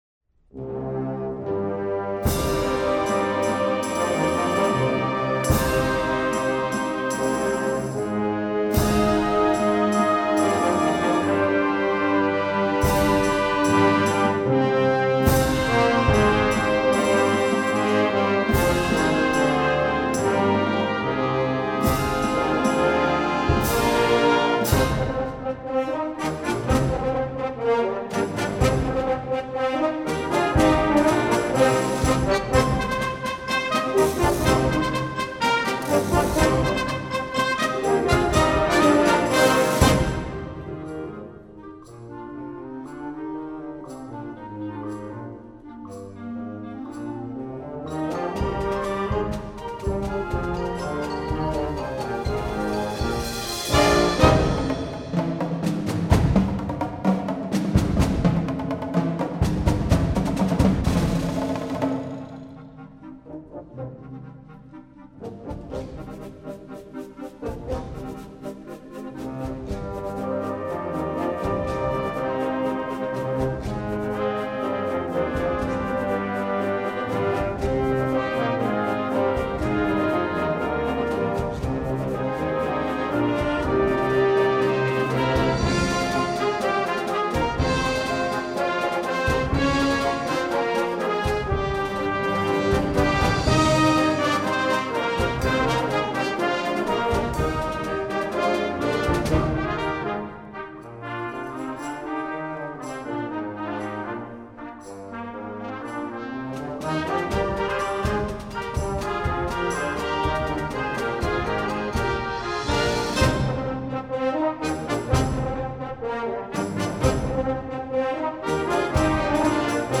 Voicing: Concert Band